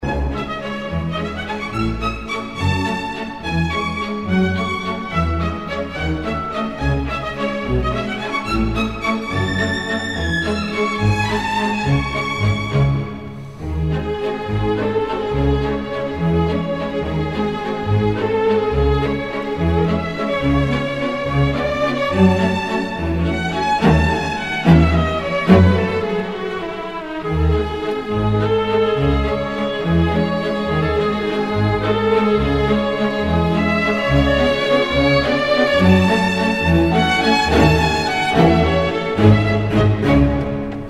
Категория: Классические | Дата: 09.12.2012|